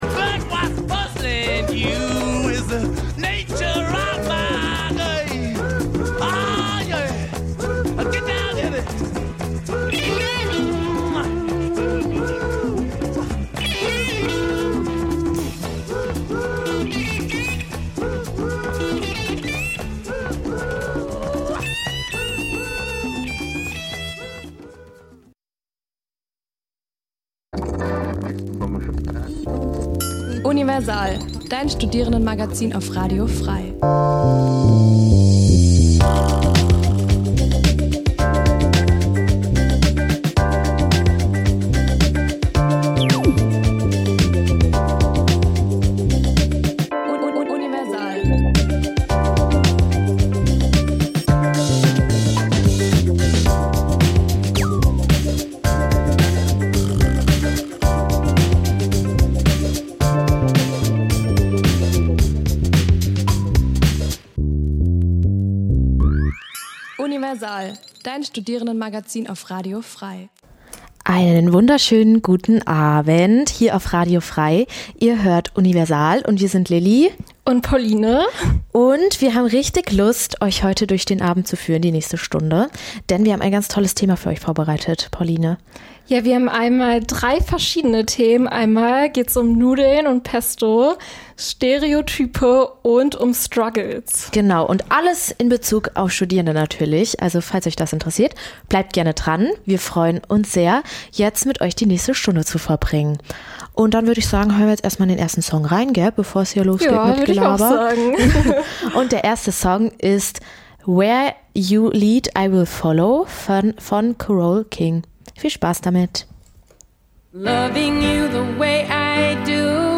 Studentisches Magazin Dein Browser kann kein HTML5-Audio.
Die Sendungen werden gemeinsam vorbereitet - die Beitr�ge werden live im Studio pr�sentiert.